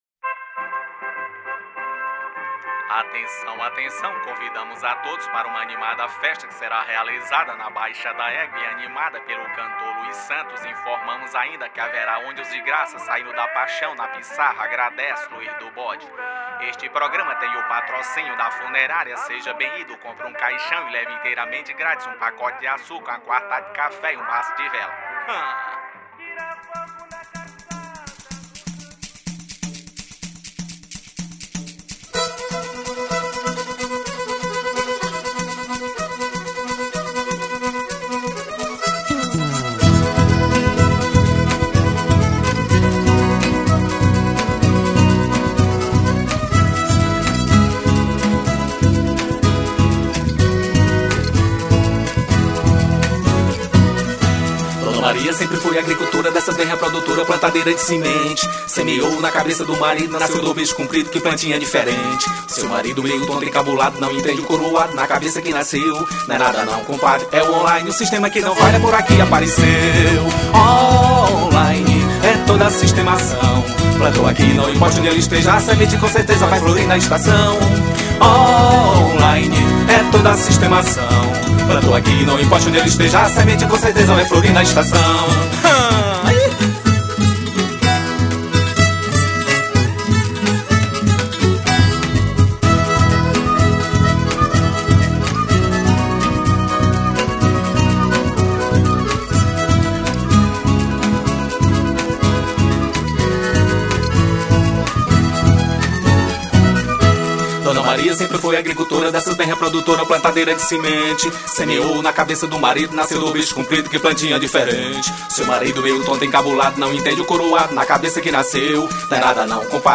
537   03:17:00   Faixa:     Forró pé de Serra
Zabumba, Baixo Elétrico 4, Viola de 12 cordas
Acoordeon
Rabeca
Percussão
Vocal